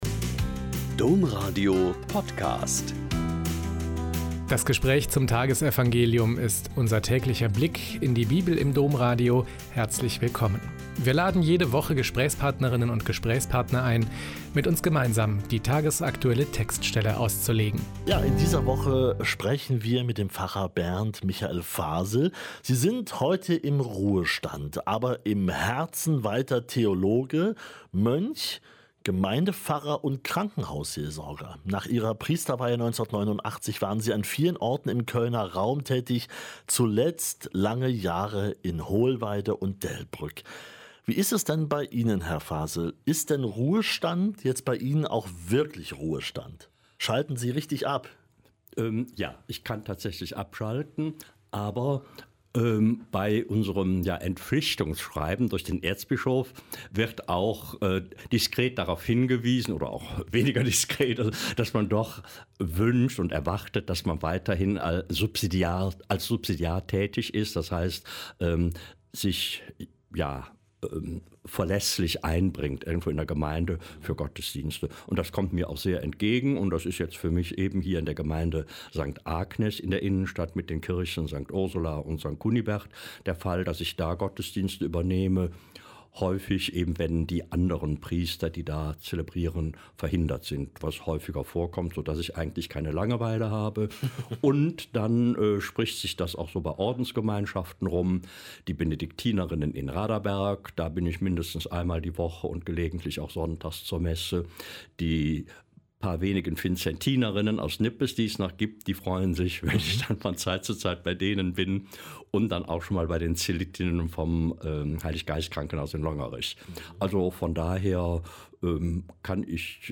Lk 12,39-48 - Gespräch